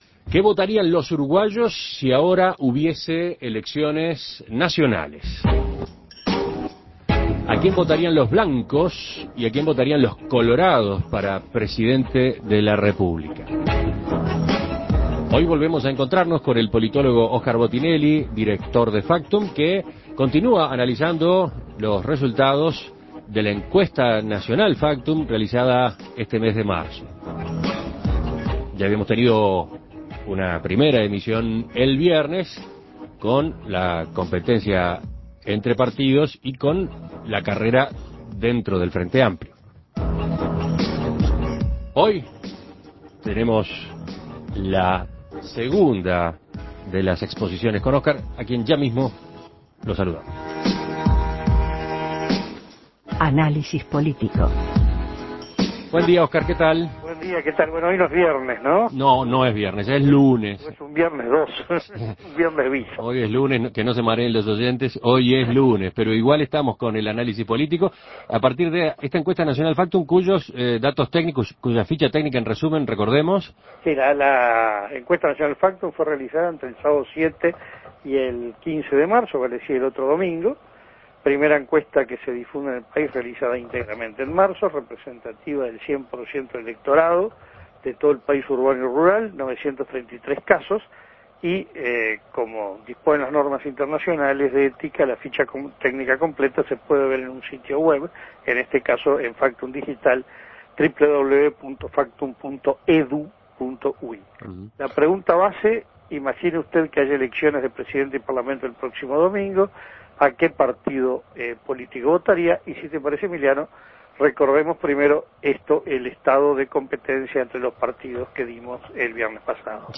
Análisis Político ¿Qué votarían hoy los uruguayos?